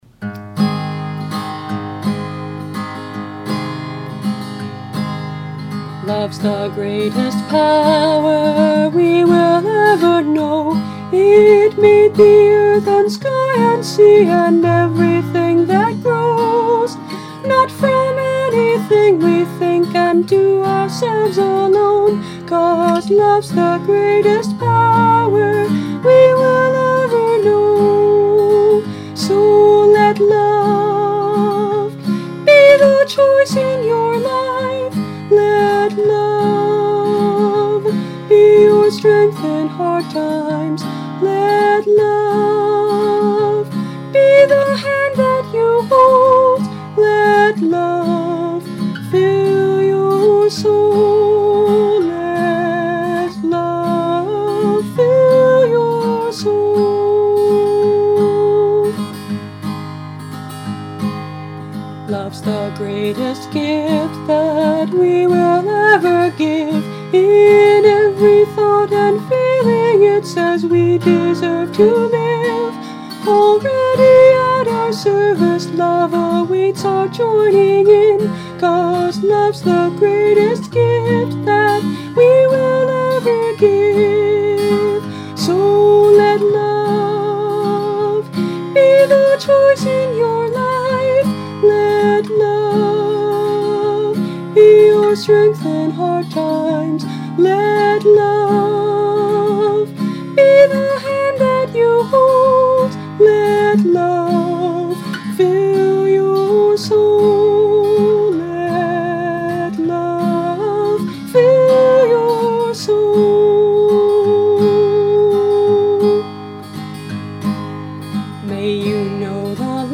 Instrument: Tempo – Seagull Excursion Folk Acoustic Guitar
(Capo 1)